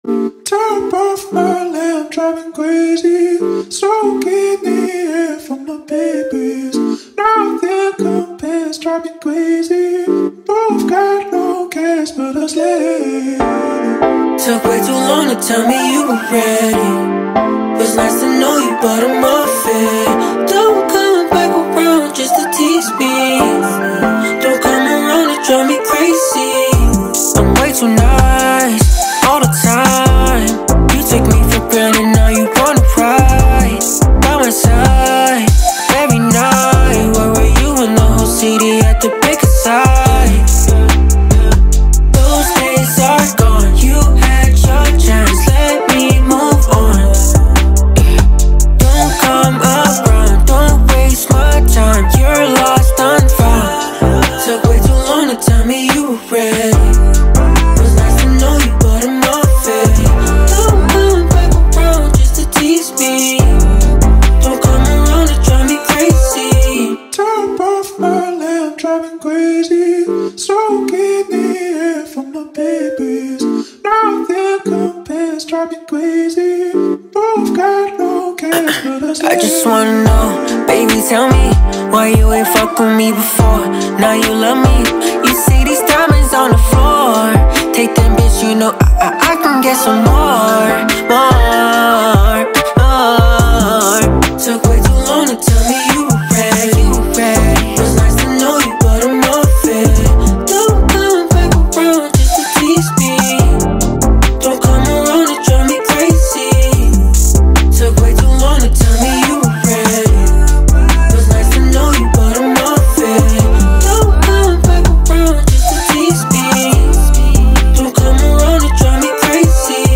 сочетая элементы поп и электроники.